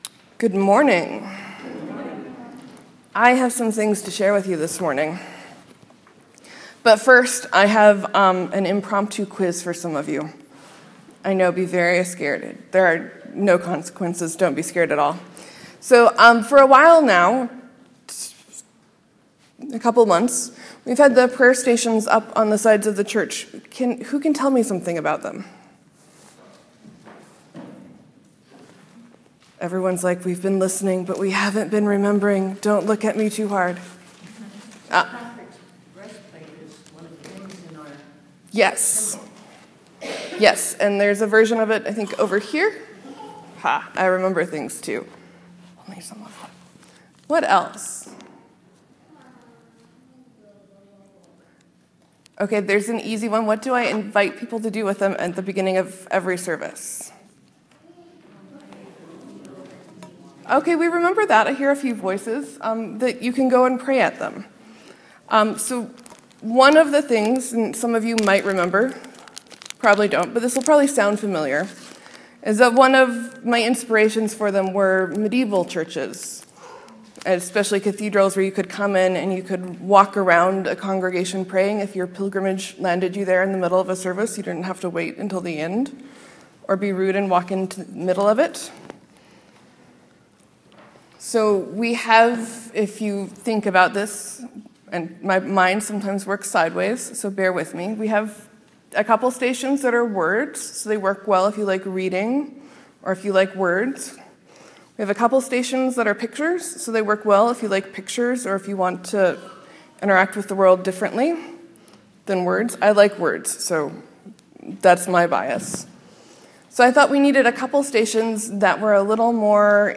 Sermon: The consolation and hope of Psalm 22.